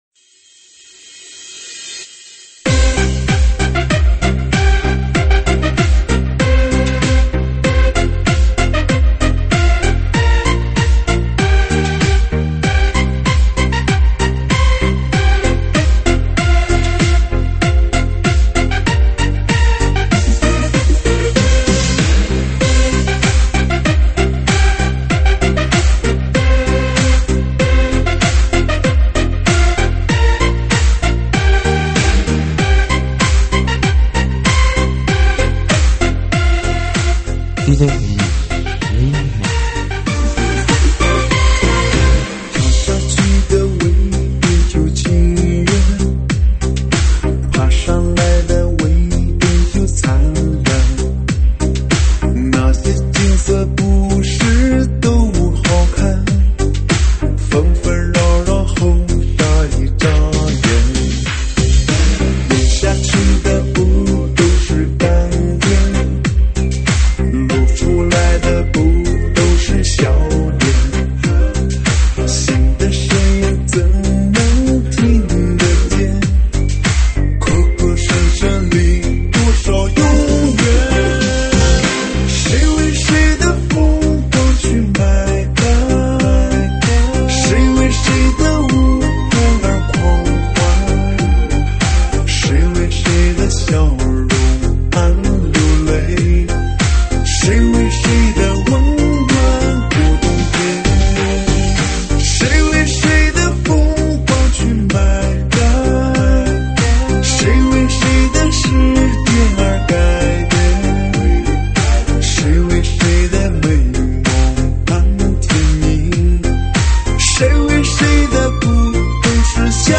栏目： 慢摇舞曲